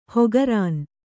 ♪ hogarān